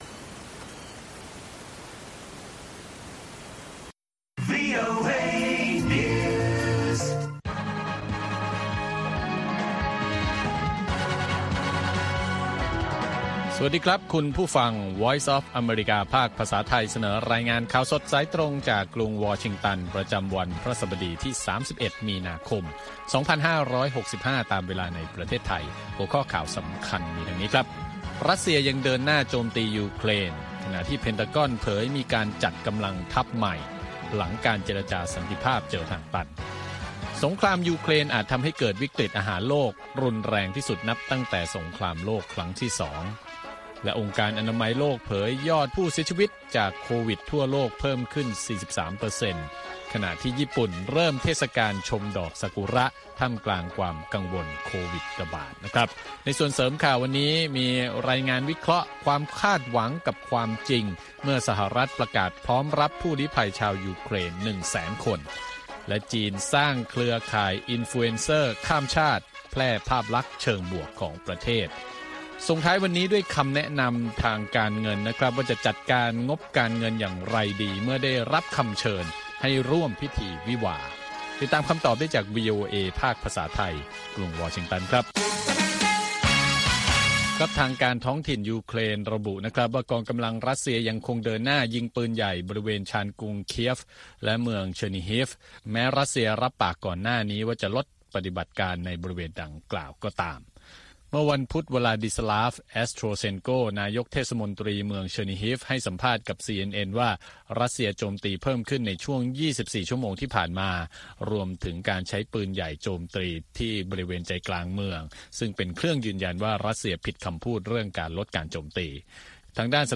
ข่าวสดสายตรงจากวีโอเอ ภาคภาษาไทย 6:30 – 7:00 น. ประจำวันพฤหัสบดีที่ 31 มีนาคม 2565 ตามเวลาในประเทศไทย